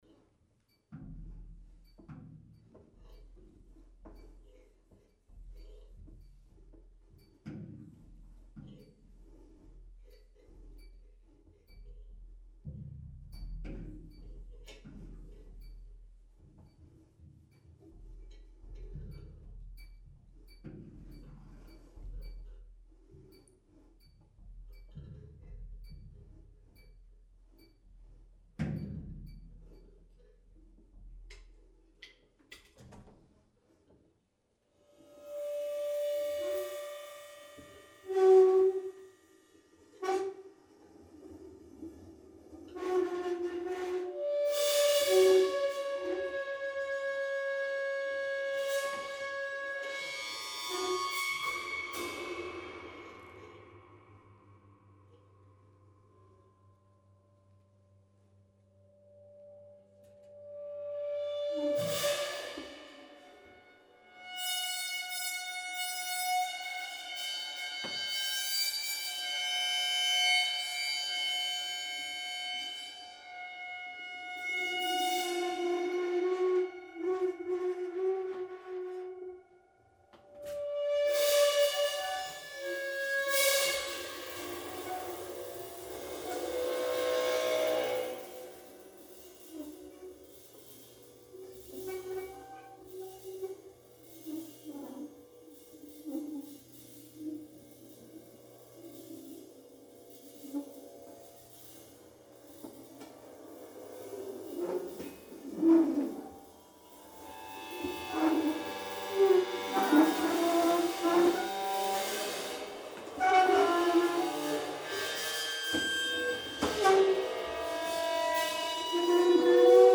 Improvisation